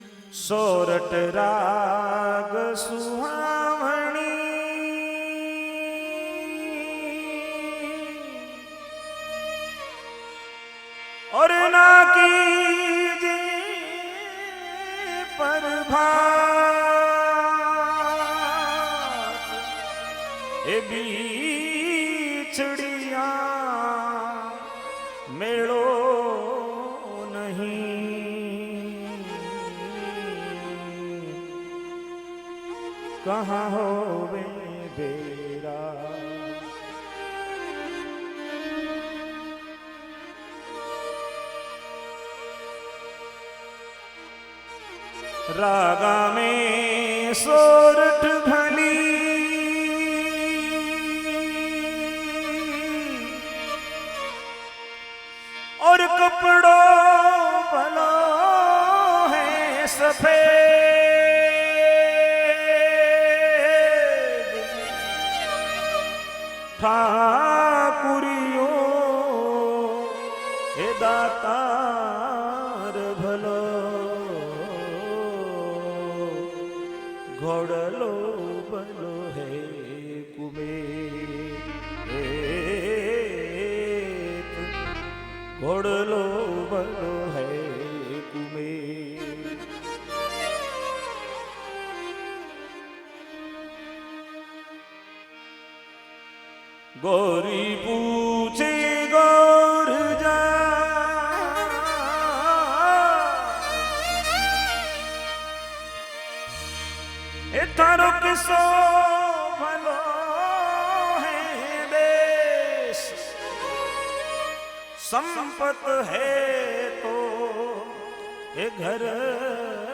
सोरठ साखी सरवर हंस चुगे मेरा भाई